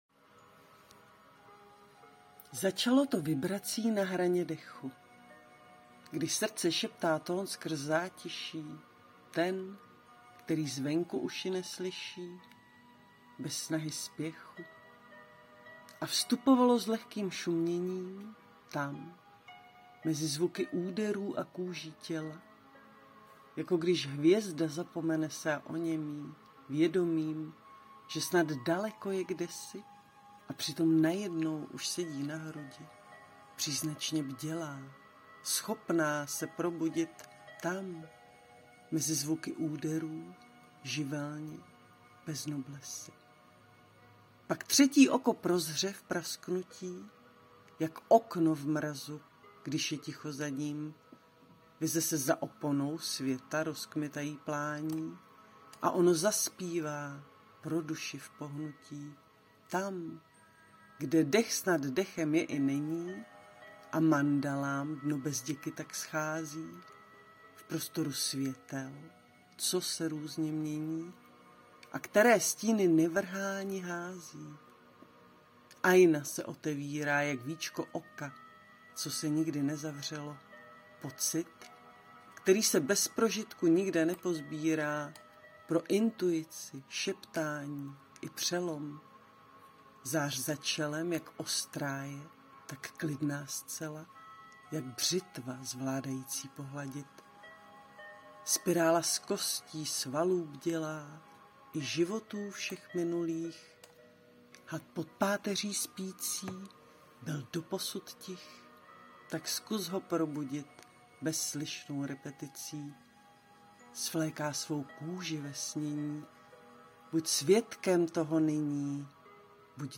Básně » Filozofické
nádherné verše príjemný prednes, hlas teším sa